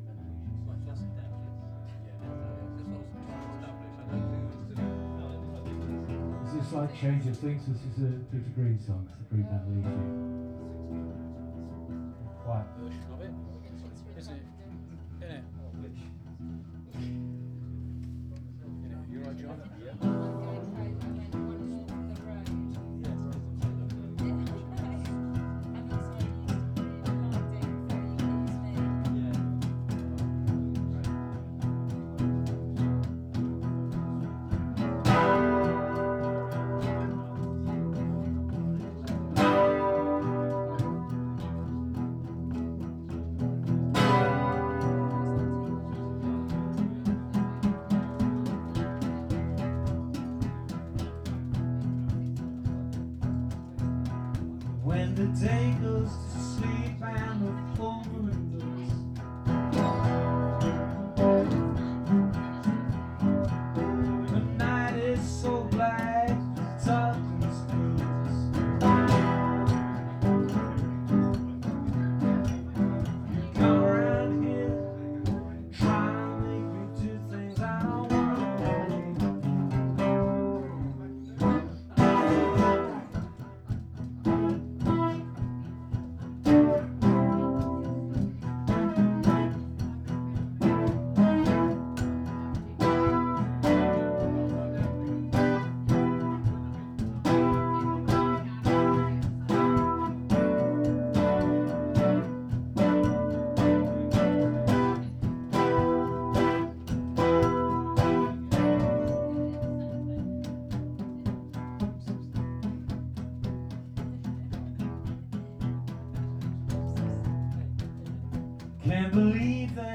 "acoustic act"
This is a small example of our live music: